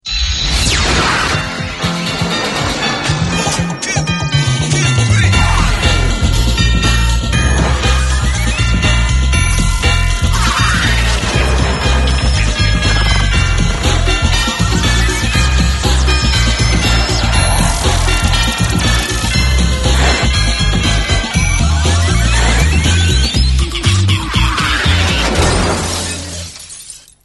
La mitica sigletta!